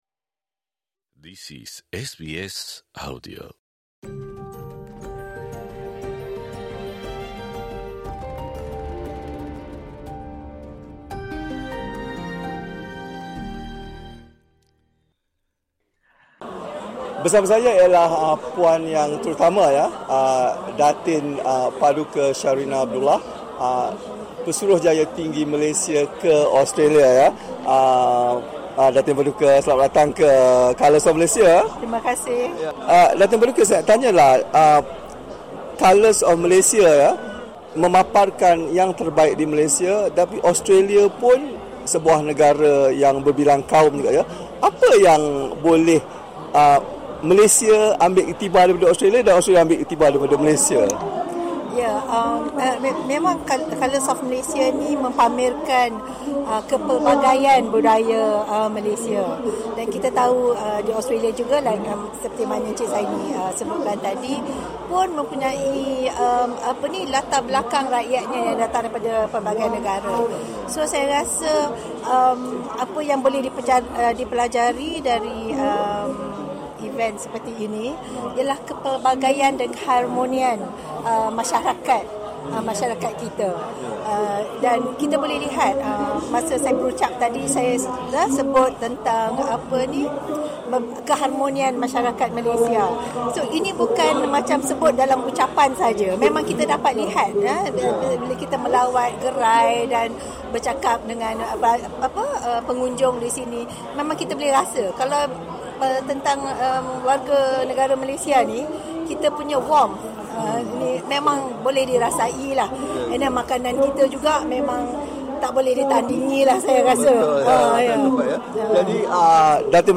Sambutan pesta Colours of Malaysia di Queen Victoria Market, Melbourne, tahun ini dirasmikan oleh Pesuruhjaya Tinggi Malaysia ke Australia, Puan Yg. Terutama, Datin Paduka Sharrina Abdullah.